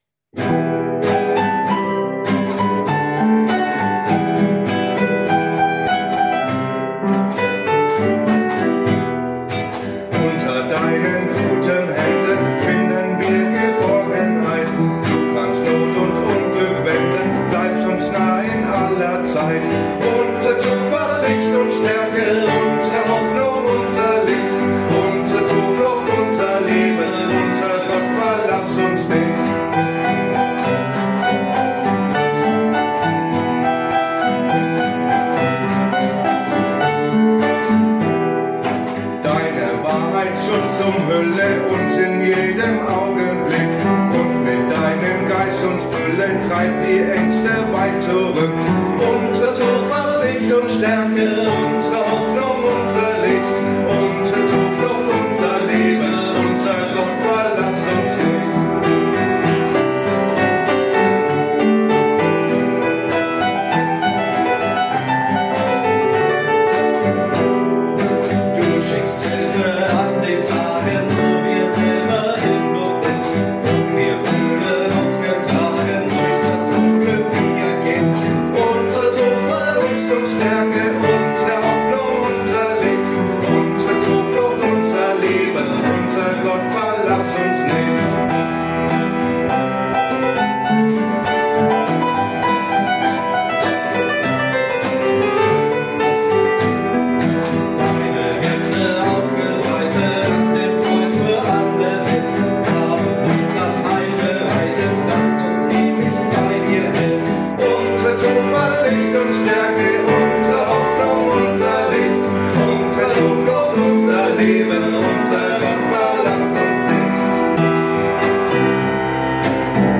Gesang, Gitarre
Klavier